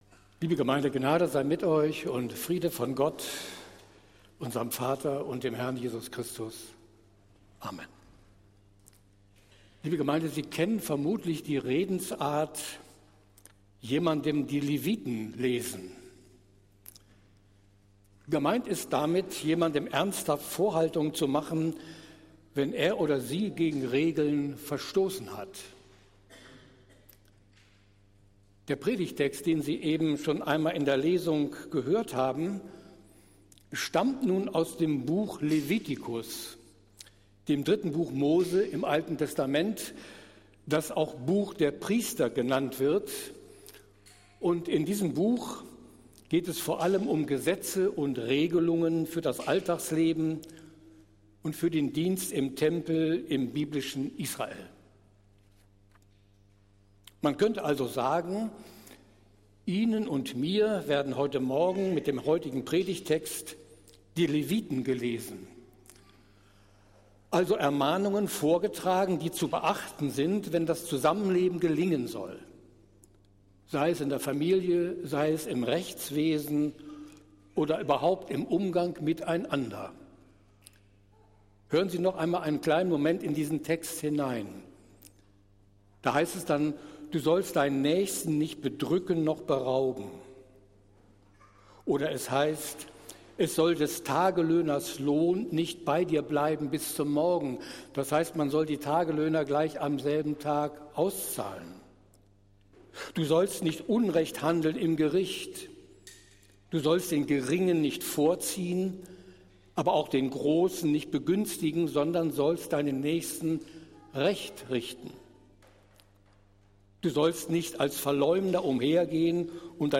Predigt des Gottesdienstes aus der Zionskirche am Sonntag, den 26. August 2024
Wir haben uns daher in Absprache mit der Zionskirche entschlossen, die Predigten zum Nachhören anzubieten.